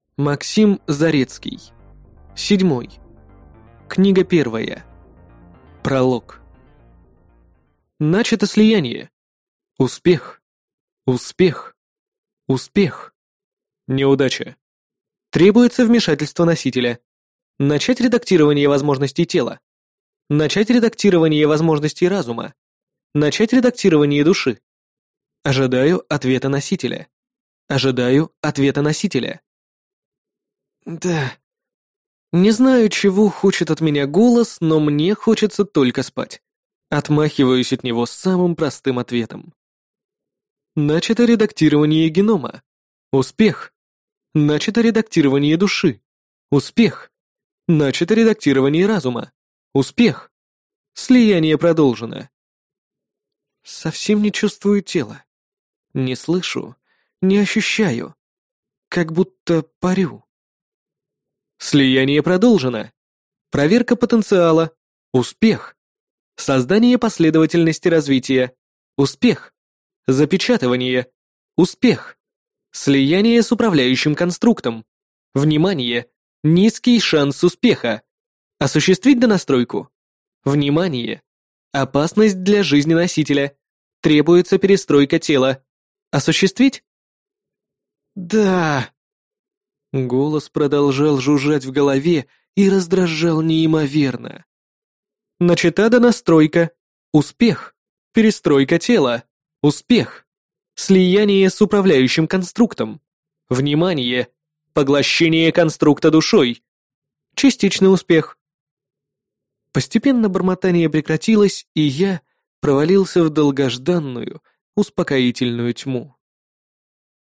Аудиокнига Седьмой | Библиотека аудиокниг
Прослушать и бесплатно скачать фрагмент аудиокниги